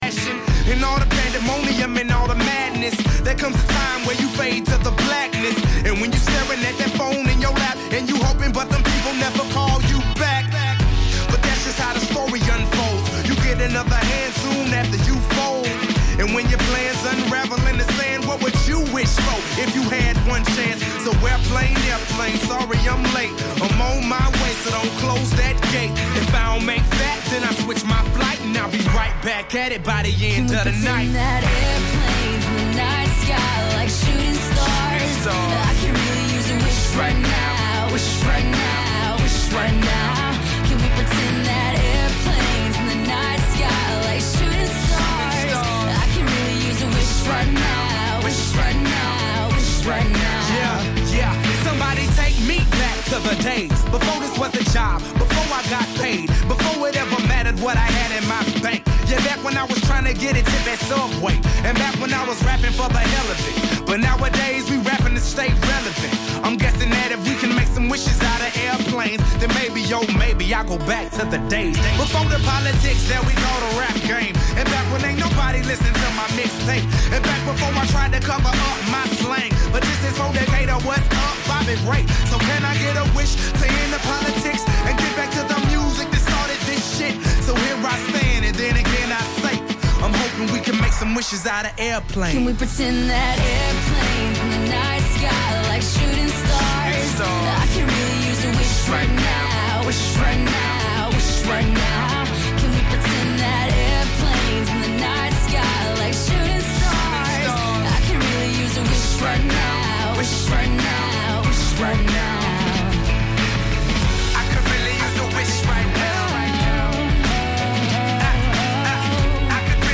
Radio Hamburg Nachrichten vom 25.07.2023 um 21 Uhr - 25.07.2023